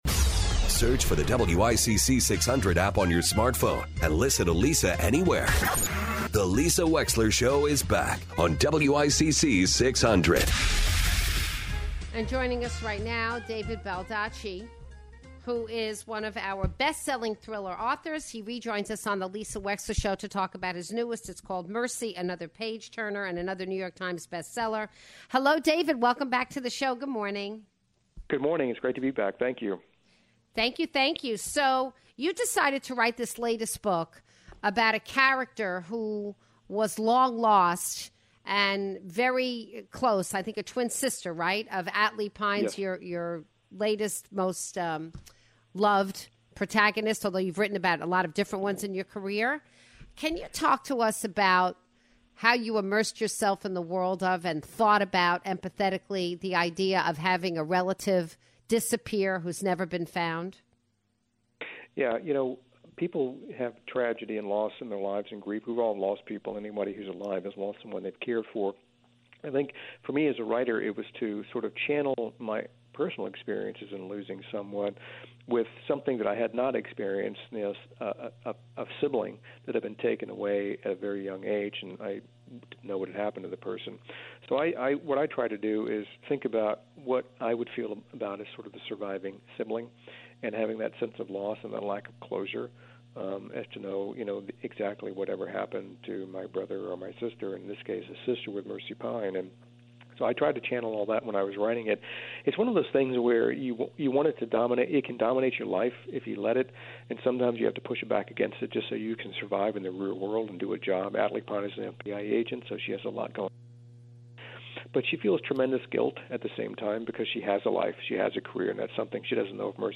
Blockbuster author David Baldacci joins the show to talk about his latest novel "Mercy".